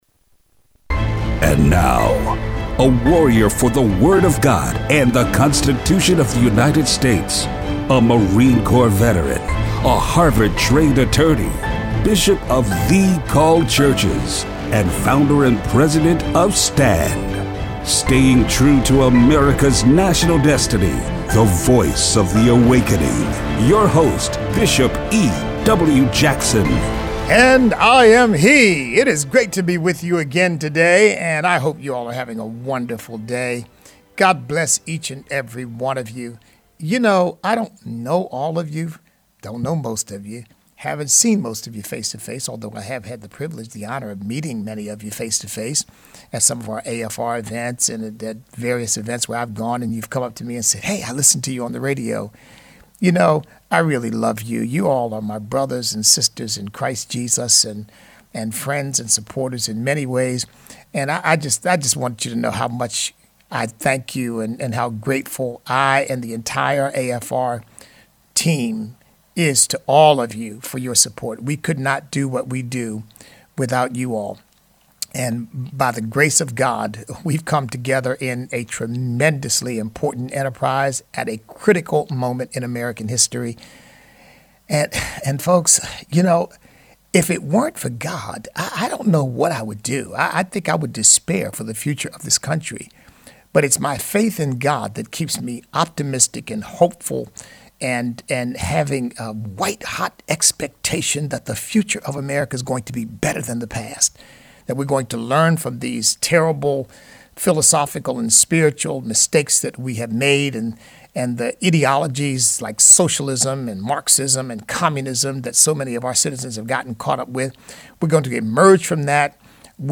Show Notes Chuck Schumer threatens Justice Gorsuch and Justice Kavanaugh over abortion case. Listener call-in.